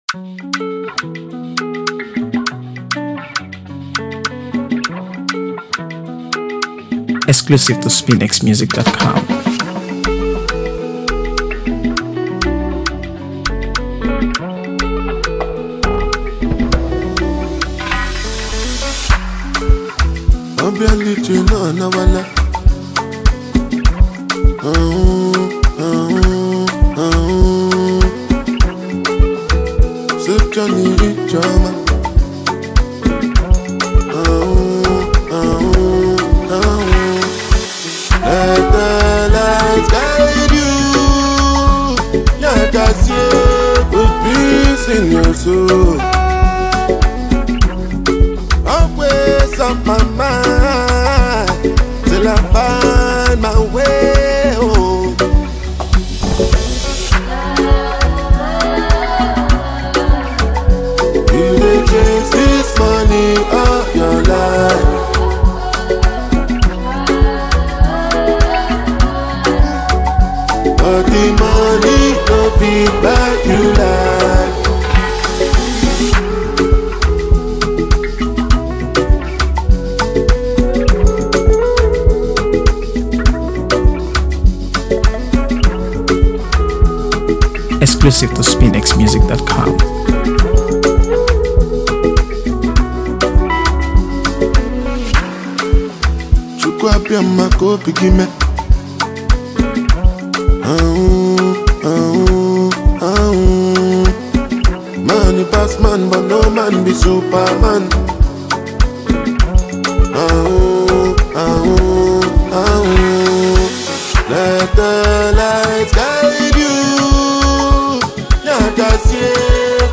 AfroBeats | AfroBeats songs
energetic and vibe-heavy track
blends catchy hooks, smooth melodies, and powerful rhythm